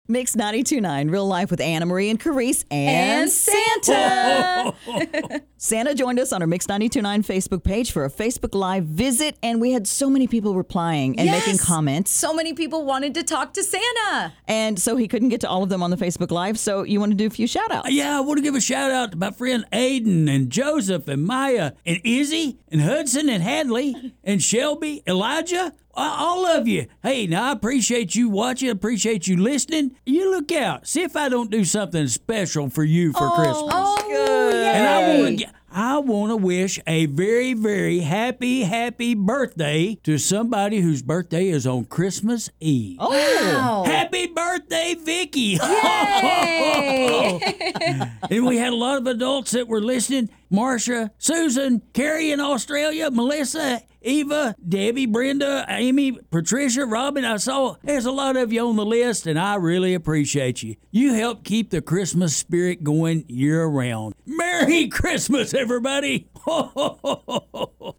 santa-shoutouts-kids-and-adults-ho-ho-merry.mp3